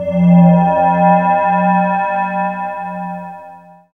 SI2 WATER 0B.wav